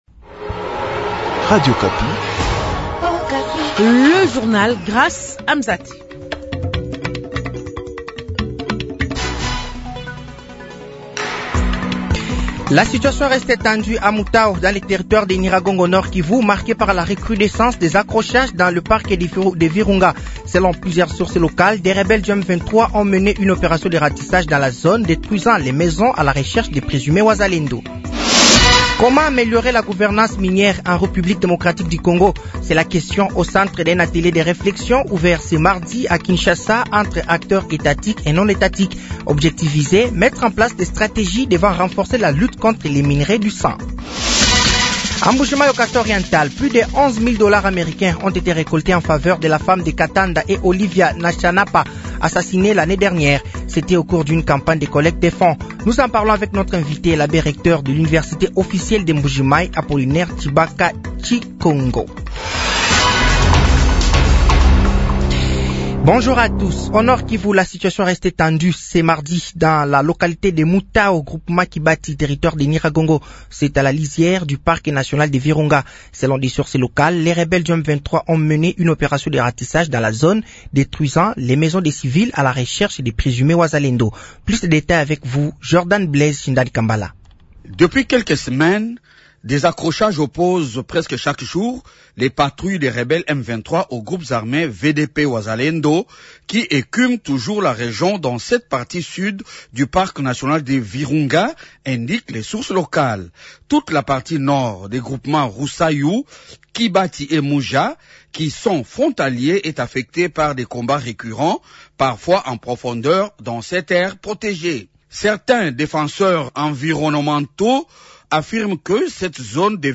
Journal français de 15h de ce mardi 15 avril 2025